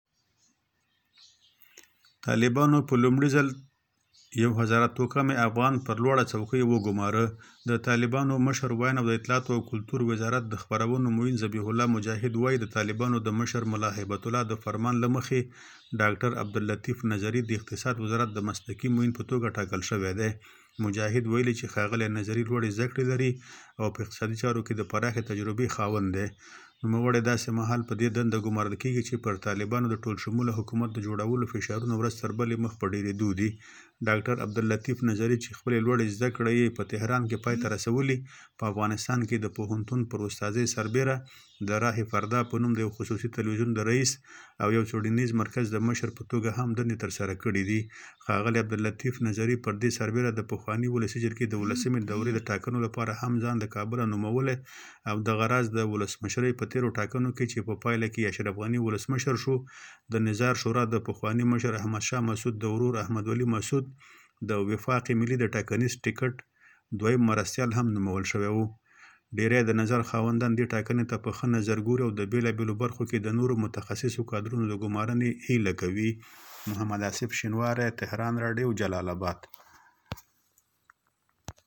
نور تفصیل ئې د تهران ریډیو د خبریال  له خولې آورو .